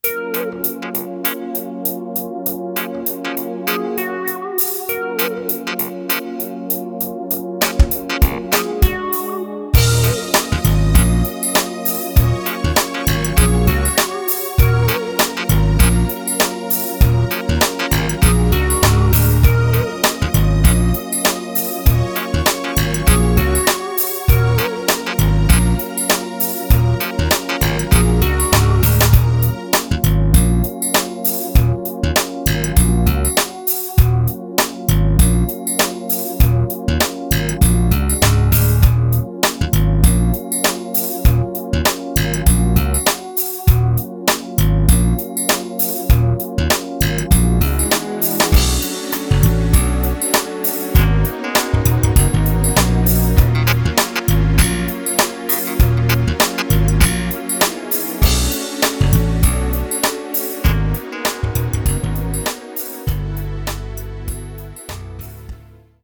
No backing horns.
Key of F minor7
Backing track only.